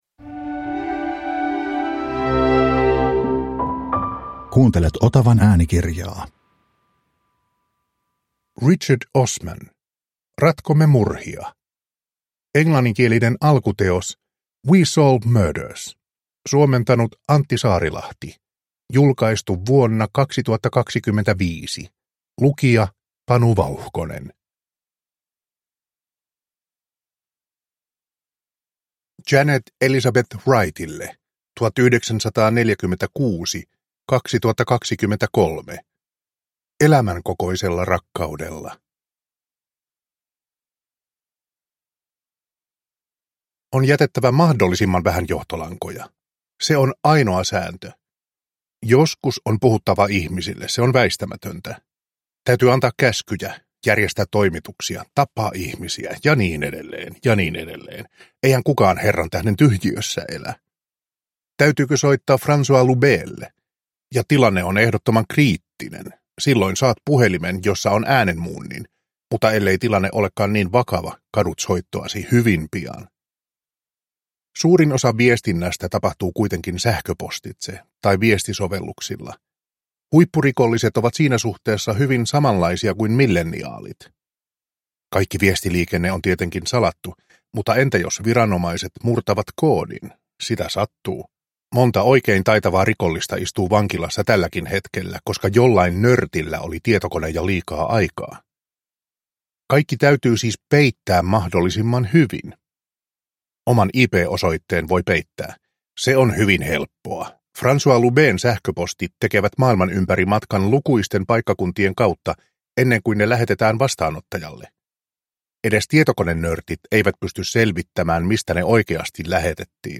Ratkomme murhia – Ljudbok